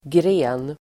Uttal: [gre:n]